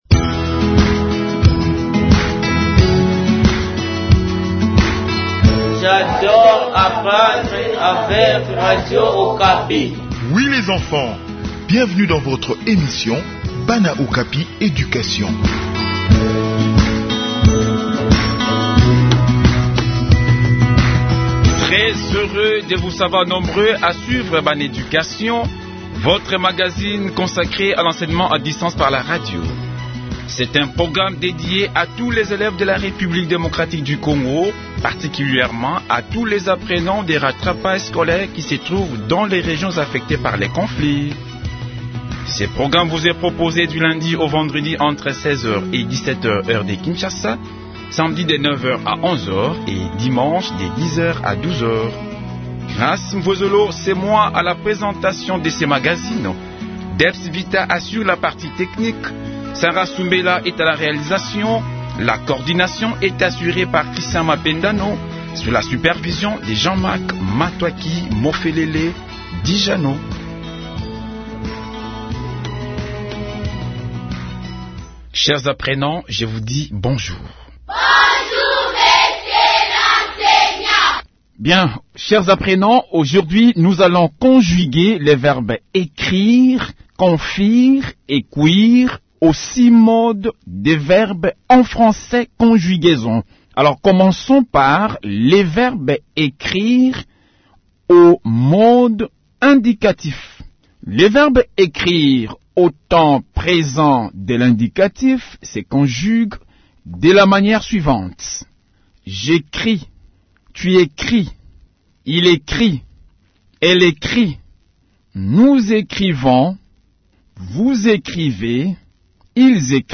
Enseignement à distance : conjugaison des verbes écrire, confire et cuire